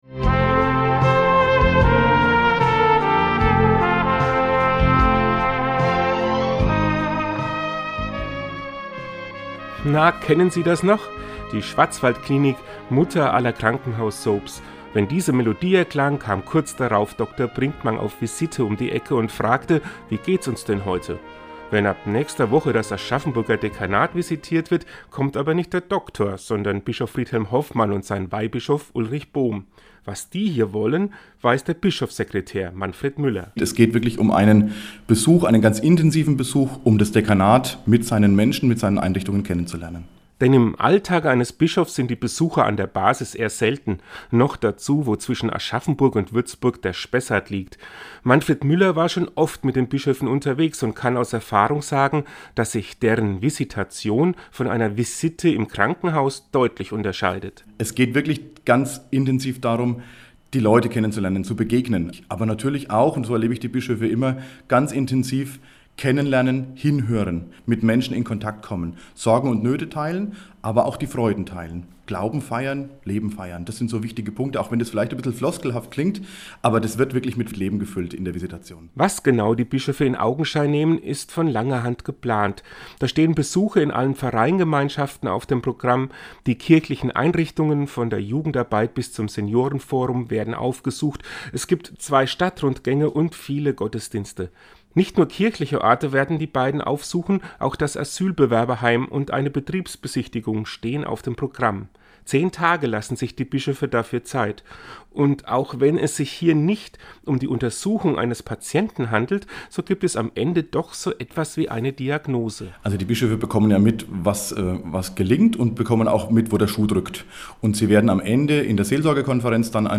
Seinen Radiobeitrag hören sie hier.